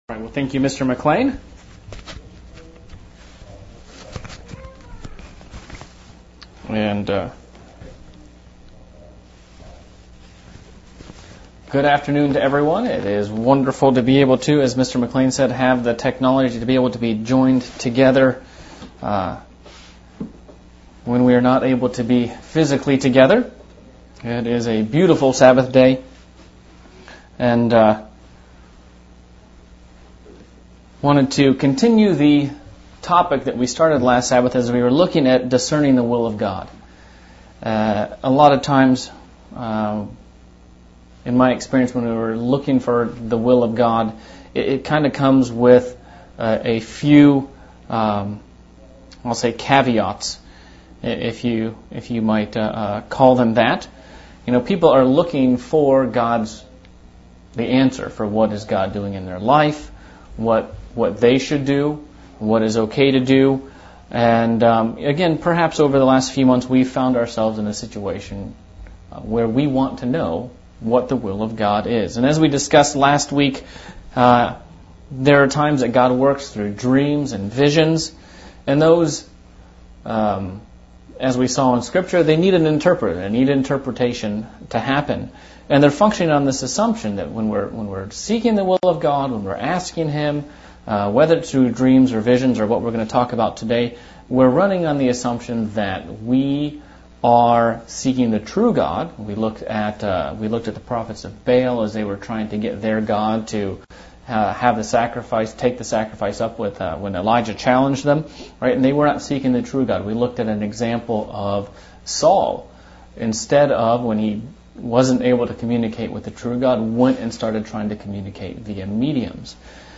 Sermon looking at how God works with his people. Looking at the aspect of Miracles.